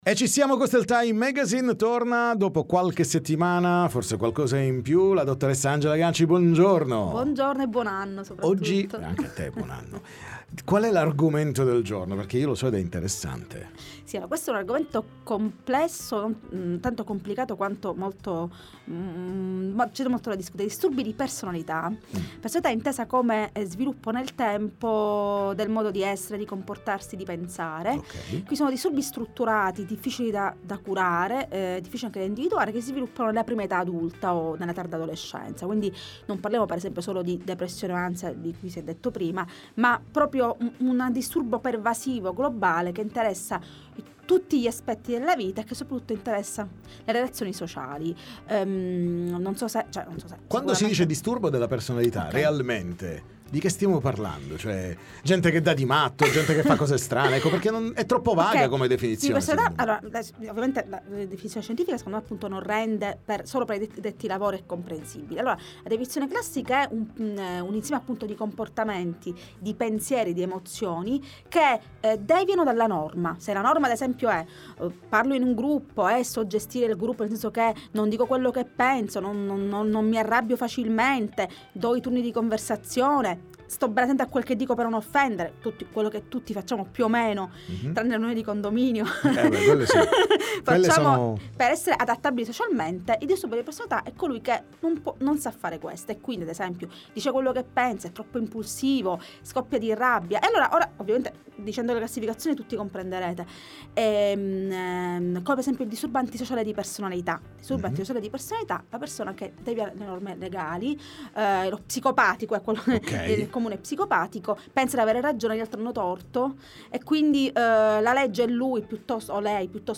parliamo con lei nei nostri studi